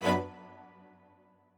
admin-leaf-alice-in-misanthrope/strings34_1_011.ogg at main